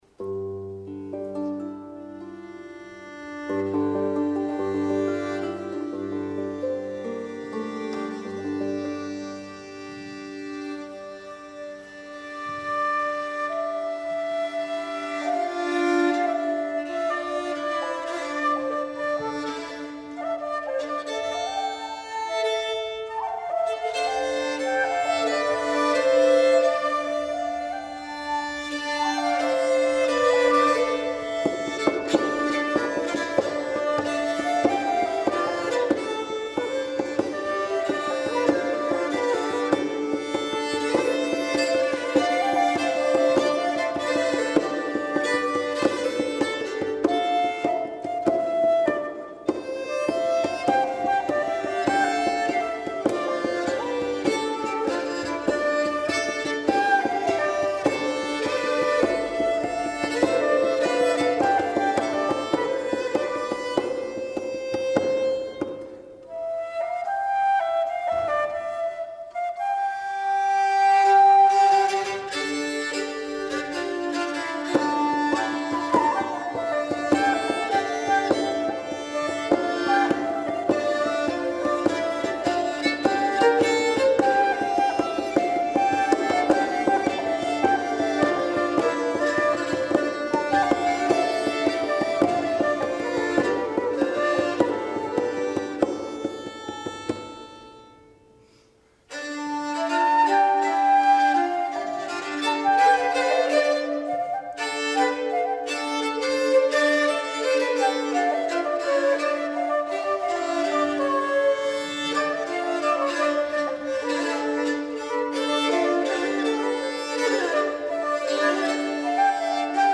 E due registrazioni dal concerto di Bellinzona, in Svizzera.